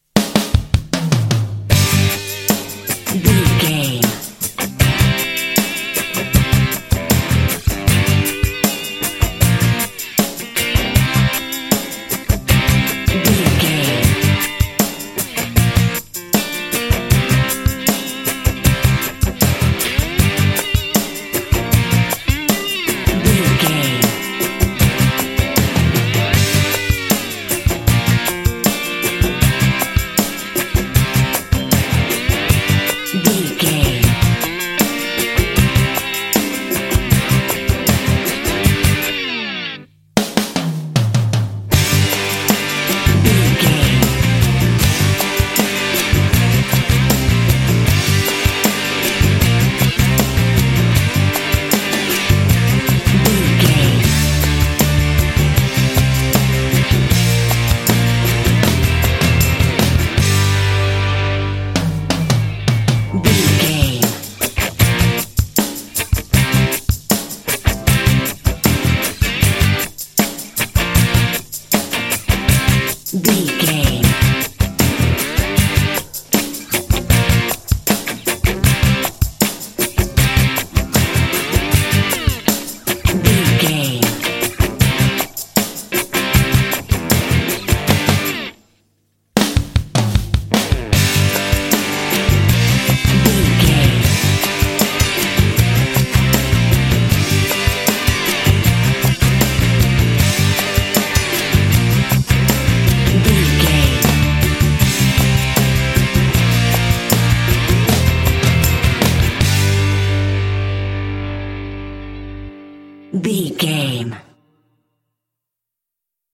Ionian/Major
driving
bouncy
happy
groovy
bright
electric guitar
drums
bass guitar
rock
alternative rock